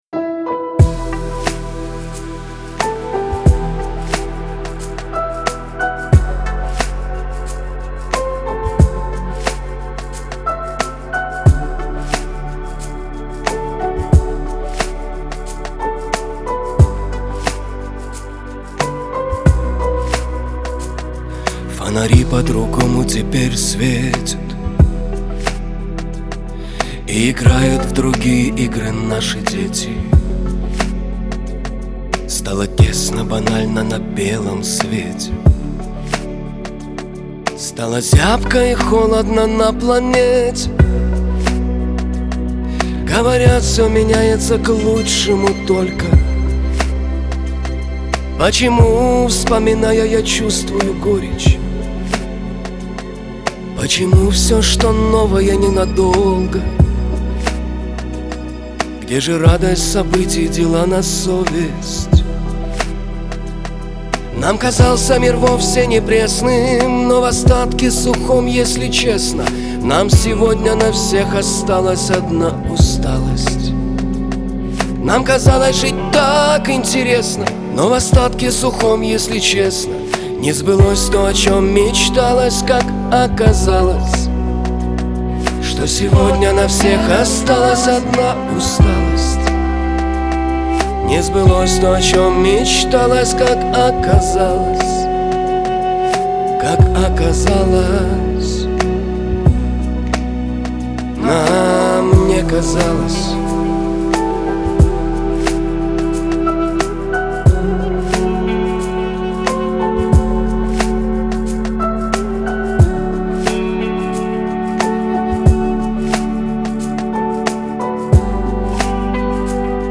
Шансон
ХАУС-РЭП